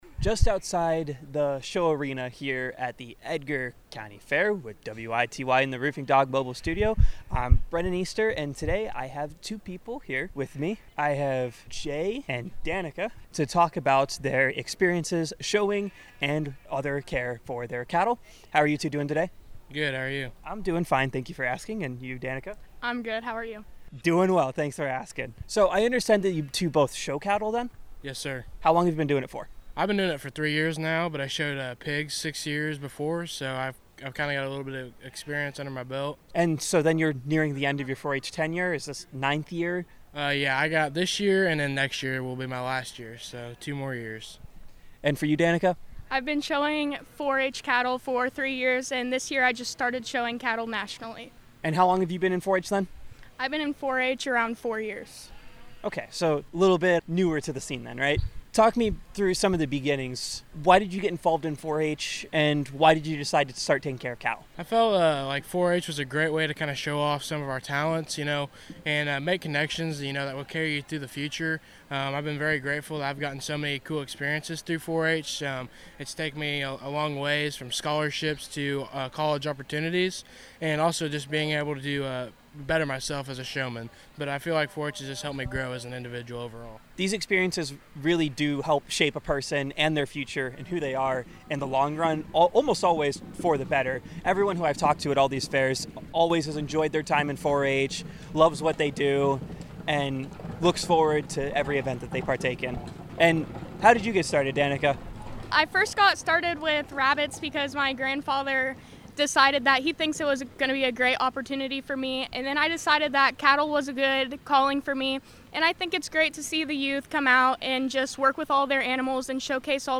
WITY’s coverage of the Edgar County Fair from the Roofing Dog Mobile Studios is presented by Diepholz Auto Group, Precision Conservation Management, Longview Bank, Edgar County Farm Bureau, Ag Prospects, Prospect Bank, Nutrien Ag Solutions in Metcalf, Chrisman Farm Center, and First Farmers Bank & Trust.